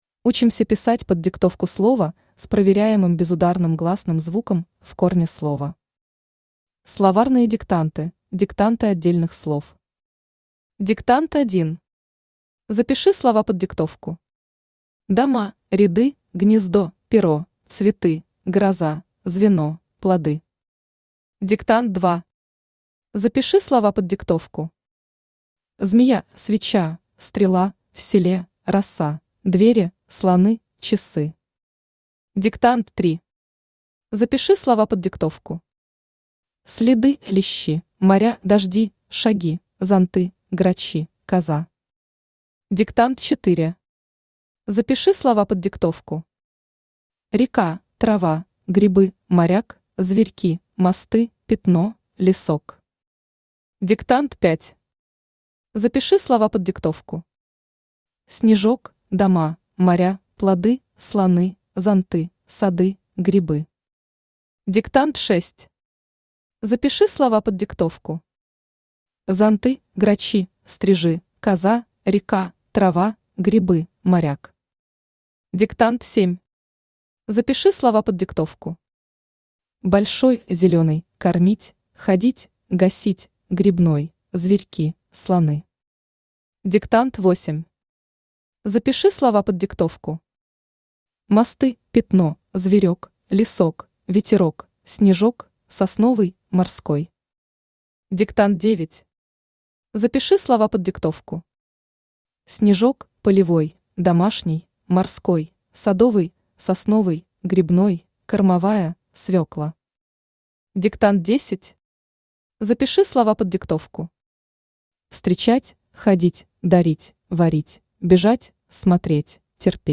Аудиокнига Диктанты одной орфограммы. Безударные гласные | Библиотека аудиокниг
Безударные гласные Автор Татьяна Владимировна Векшина Читает аудиокнигу Авточтец ЛитРес.